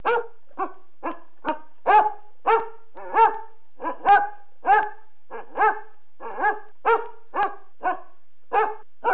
Sonidos de animales de granja - Descargar gratis